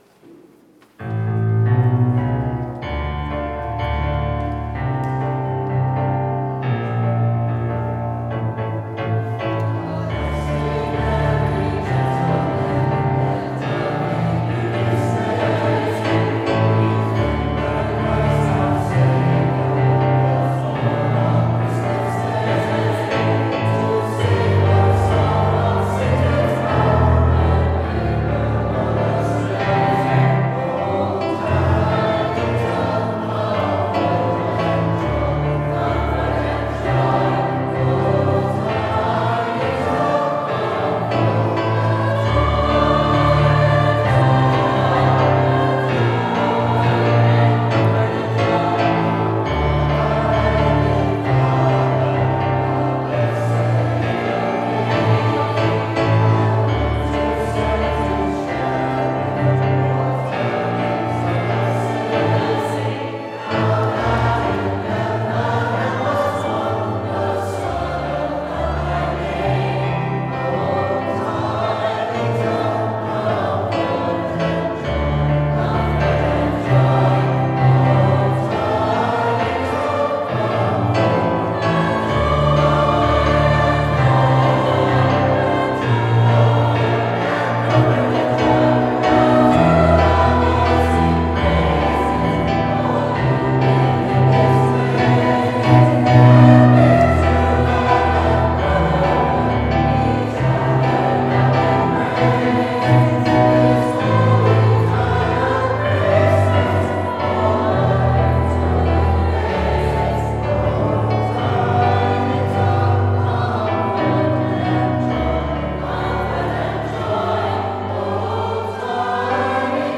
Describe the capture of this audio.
December 8, 2024 - St. Philomena Christmas Concert